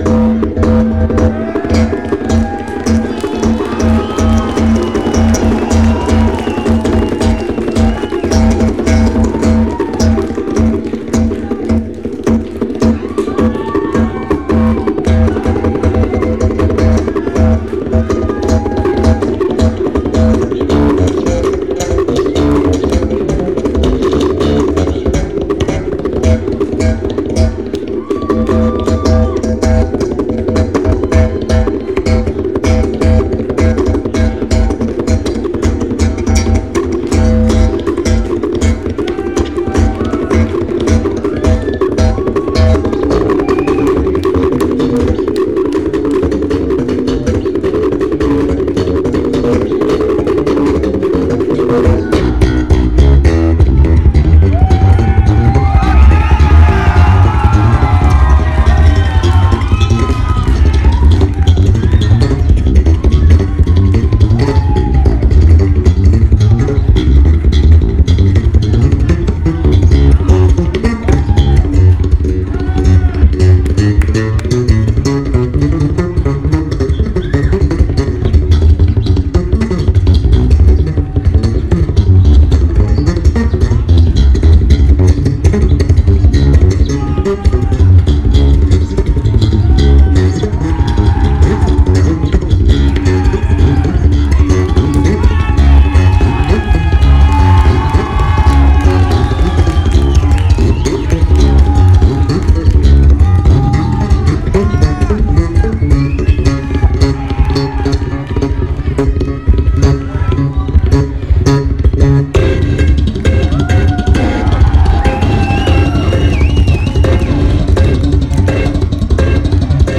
location Phoenix, Arizona, USA venue Old Brickhouse Grill